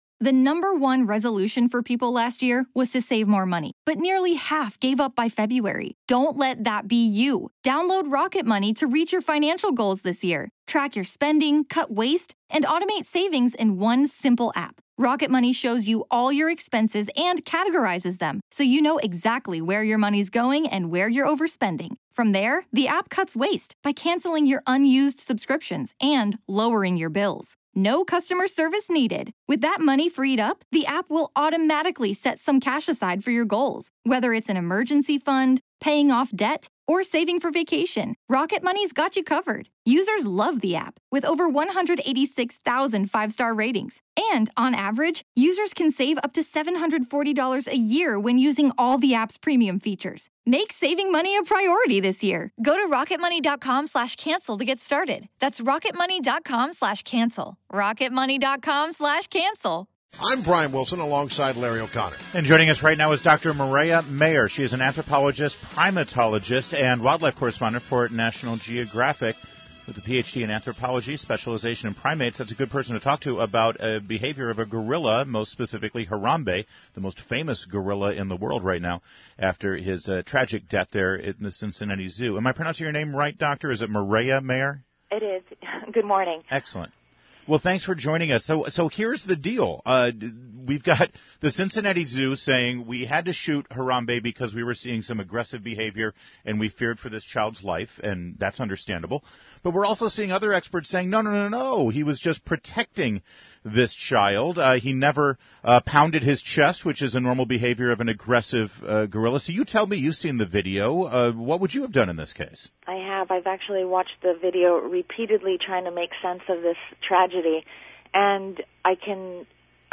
INTERVIEW -- DR. MIREYA MAYOR - Anthropologist, Primatologist. and wildlife correspondent for the National Geographic.